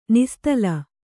♪ nistala